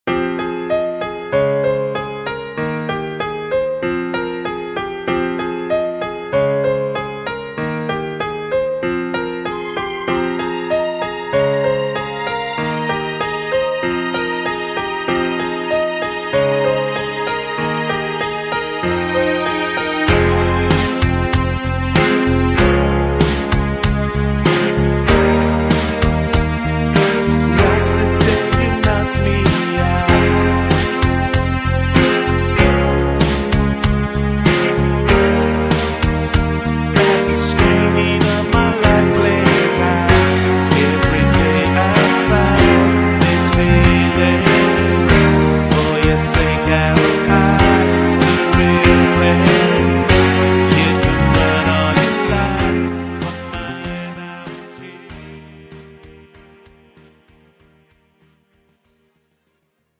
팝송) MR 반주입니다.